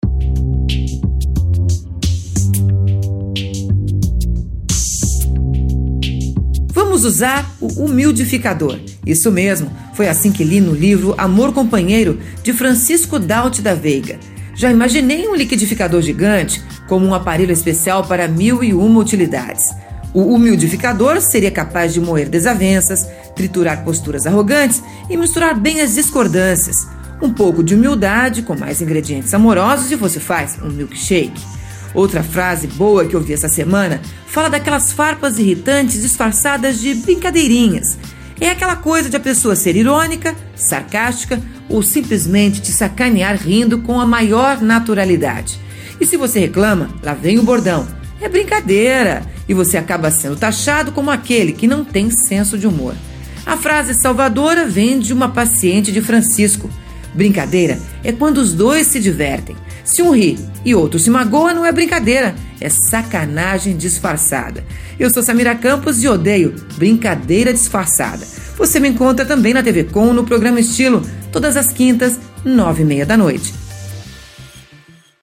comentário
na rádio Itapema FM - SC